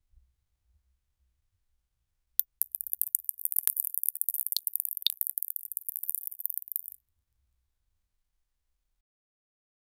Create and download royalty-free crystals sound effects.
small-crystal-rock-fallin-pc6qlseb.wav